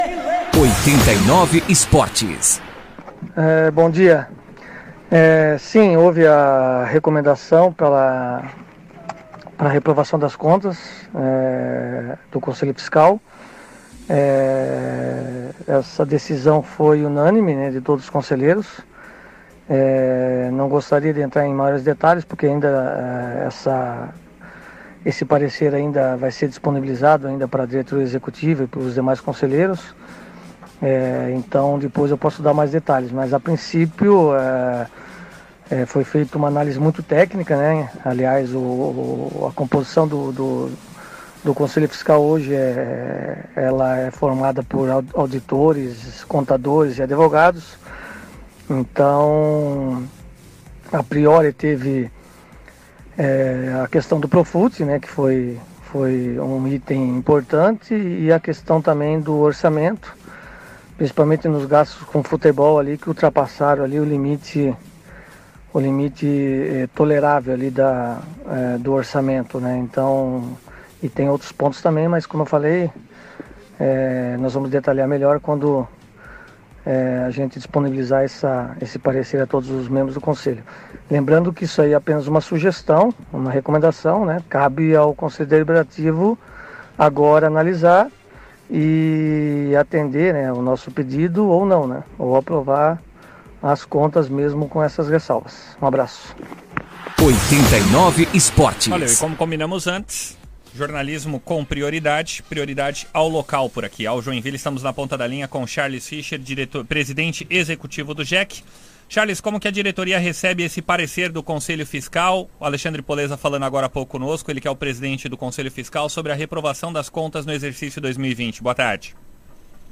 O clima esquentou durante um programa de rádio em Joinville na tarde desta quinta-feira (20).